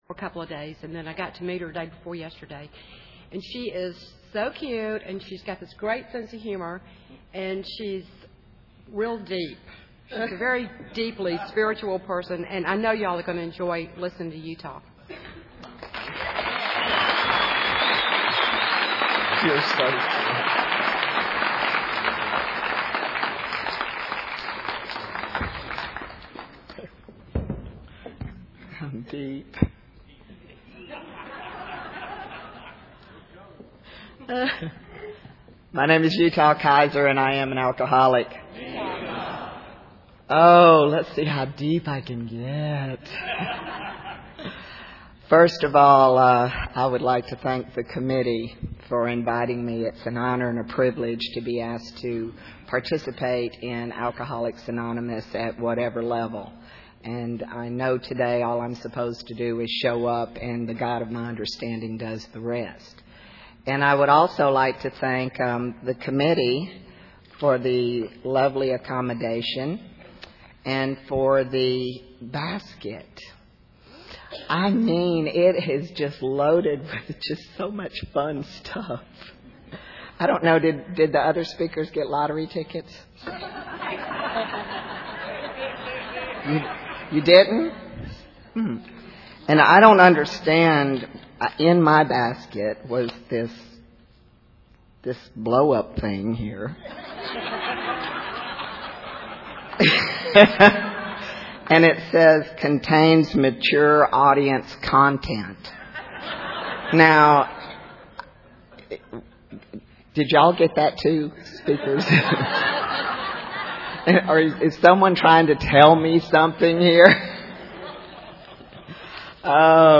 GA EVENT: 53rd Georgia State Convention TYPE: AA, Female, Story Pronounced like "Utah"